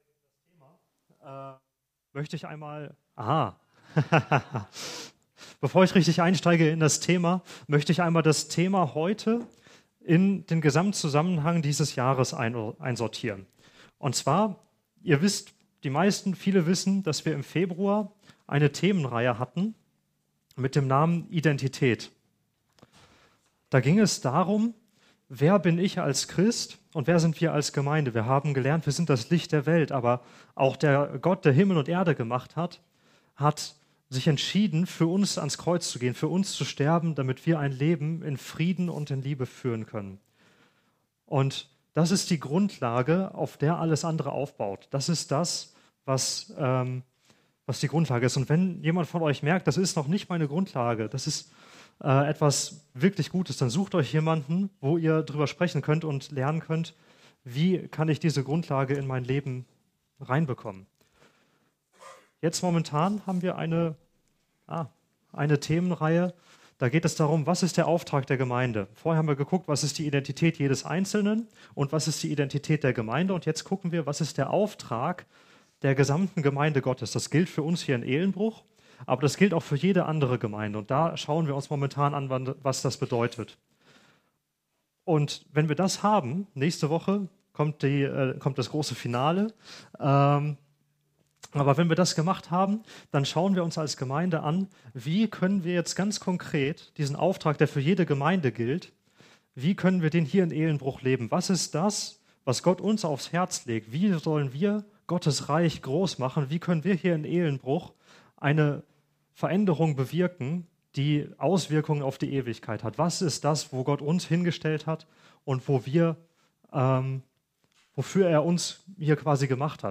Predigt vom 24. März 2019 – efg Lage